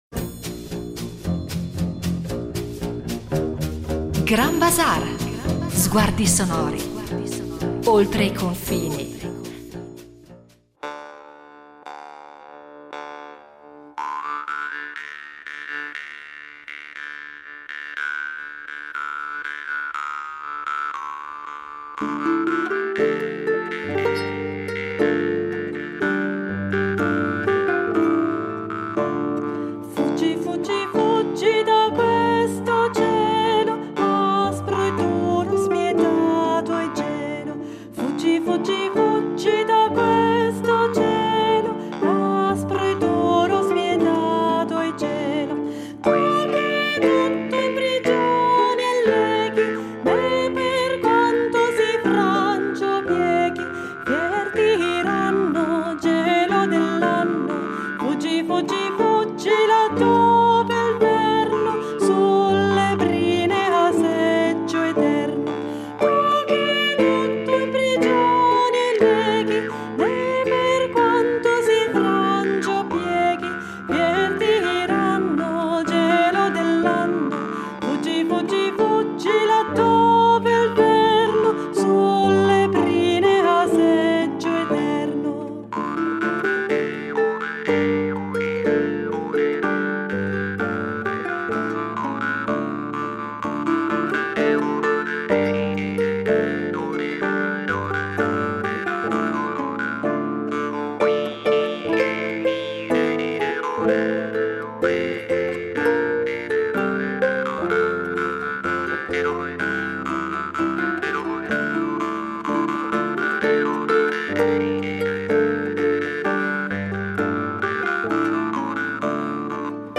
con la sua voce intensa e ricca di espressione